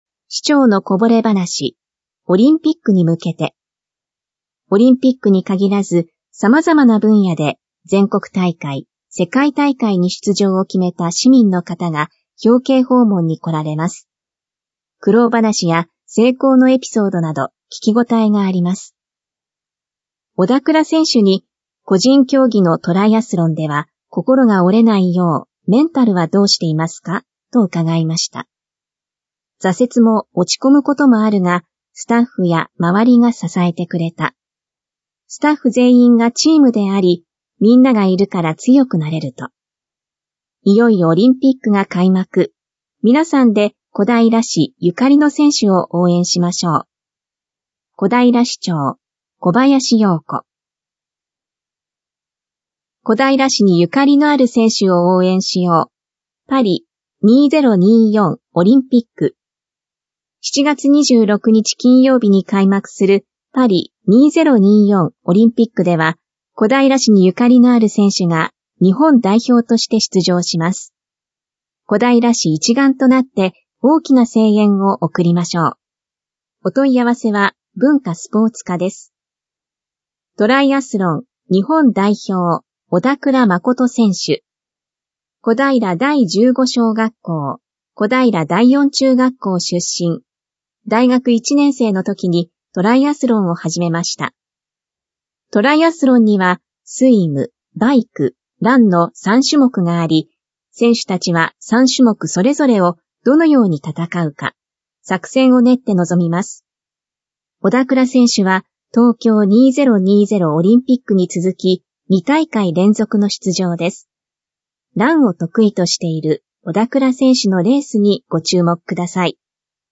市報こだいら2024年7月20日号音声版